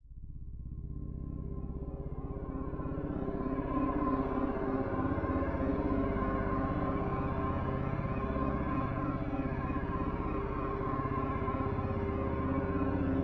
Strange Texture ( A Minor 145 bpm).wav